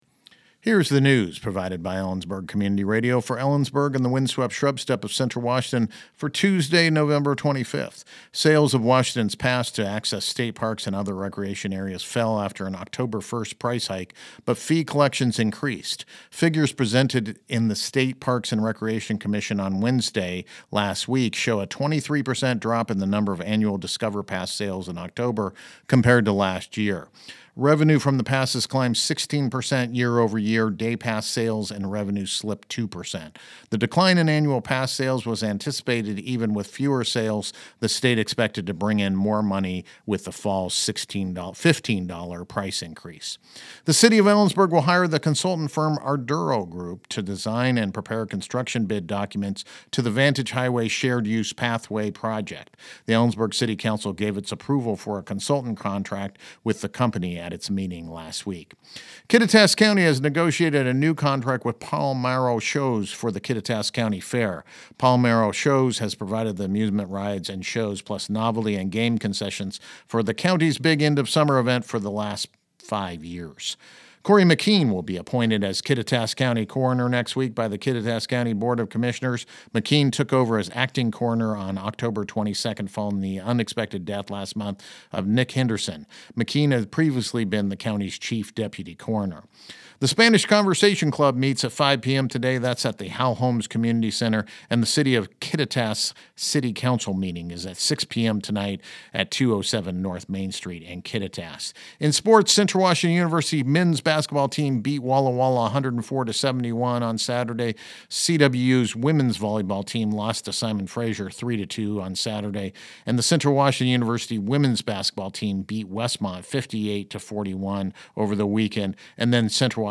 LISTEN TO THE NEWS HERENEWSSales of Washington’s pass to access state parks and other recreation areas fell after an Oct. 1 price hike, but fee collections increased.